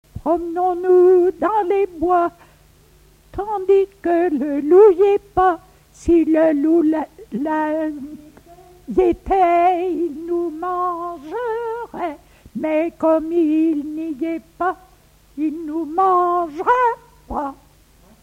Enfantines - rondes et jeux
Pièce musicale inédite